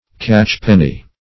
Catchpenny \Catch"pen*ny\, a.